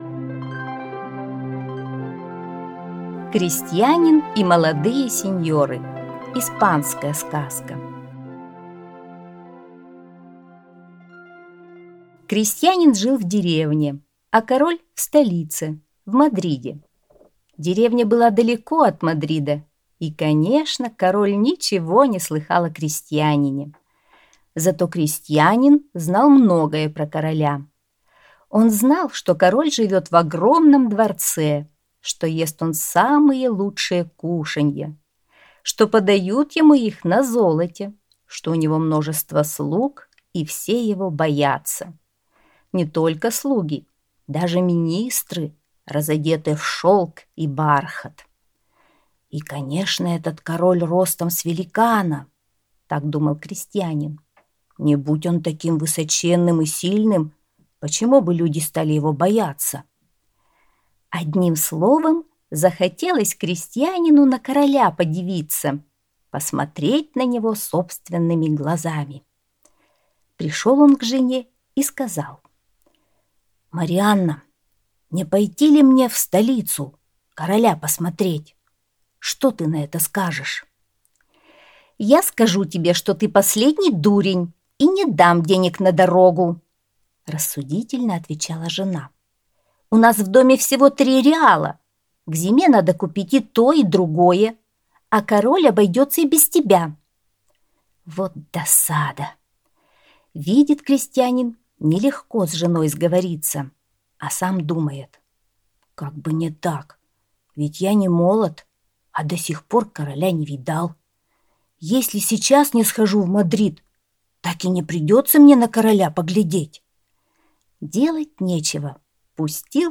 Крестьянин и молодые сеньоры - испанская аудиосказка